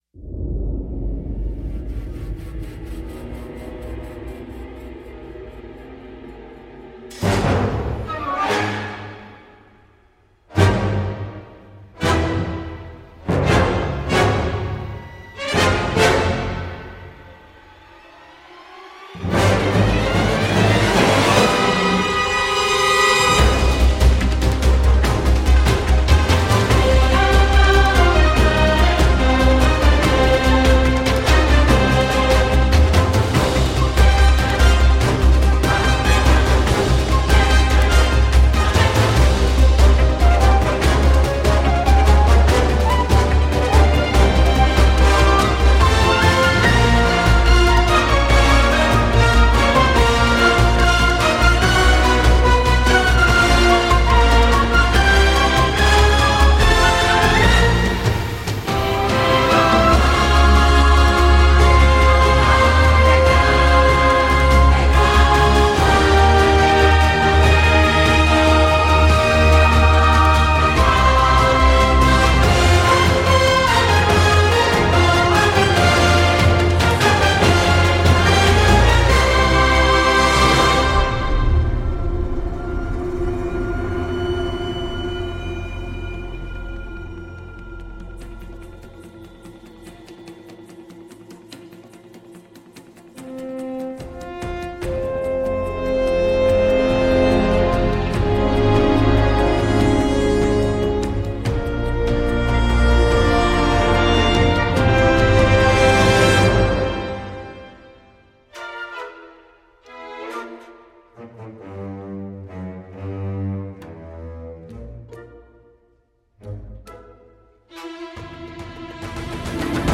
Mélange électro et orchestre avec chœurs.